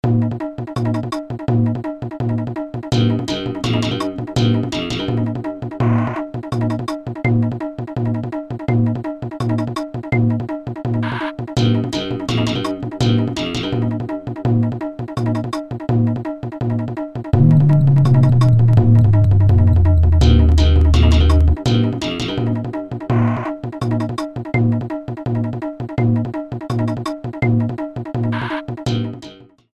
Trimmed, added fadeout
Fair use music sample